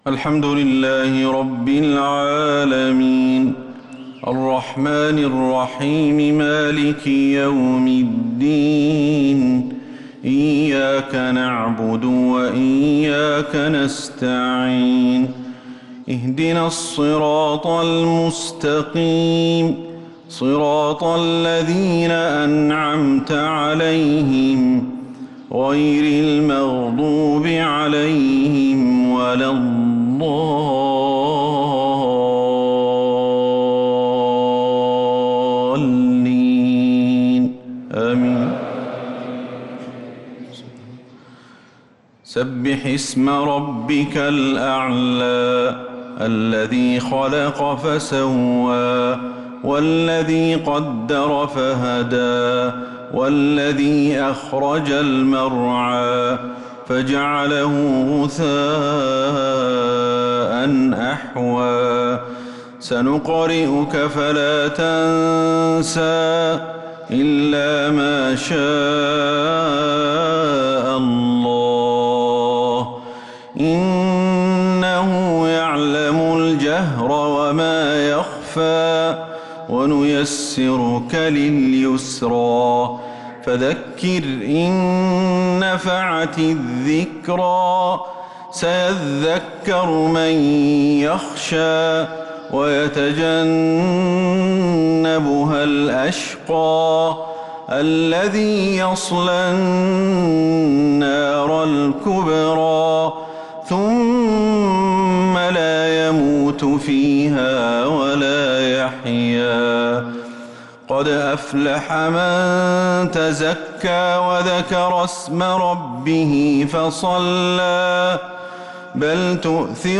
صلاة الجمعة 7-9-1446هـ سورتي الأعلى و الغاشية كاملة | Jumu'ah prayer Surat al-A`la & al-Ghashiya 7-3-2025 > 1446 🕌 > الفروض - تلاوات الحرمين
madinahJumuah.mp3